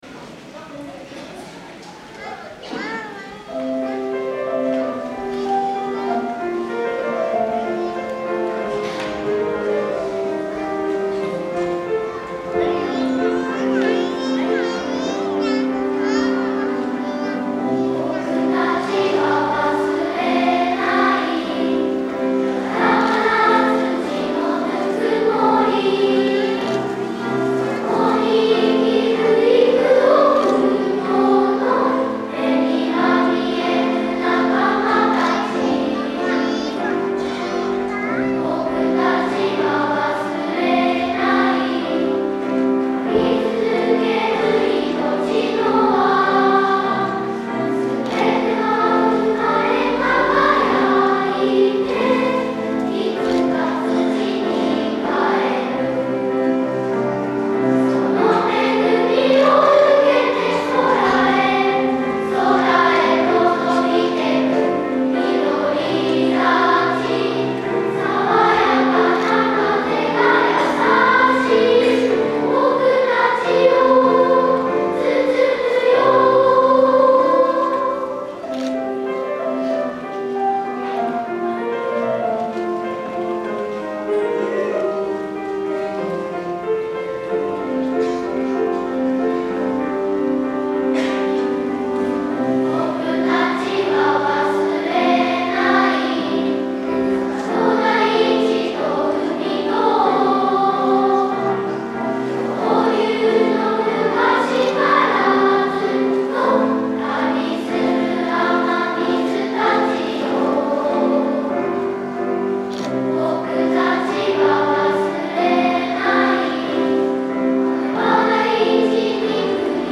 3・4年生（中学年チーム）　2部合唱「
今回はラップのように言葉を伝えるチームと、ハーモニーをつくるチームに分かれて歌うことにチャレンジ！！
『いのちはつながっていく』というメッセージを子どもたちなりに受けとめ、天使の歌声で表現していました♪